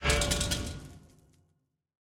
Minecraft Version Minecraft Version snapshot Latest Release | Latest Snapshot snapshot / assets / minecraft / sounds / block / trial_spawner / detect_player1.ogg Compare With Compare With Latest Release | Latest Snapshot